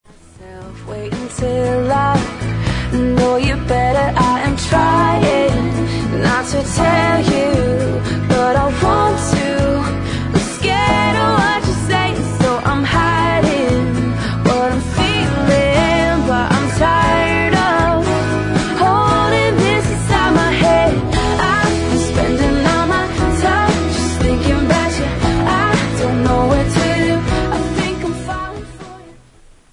• Pop Ringtones
Musically, the song is a pop rock ballad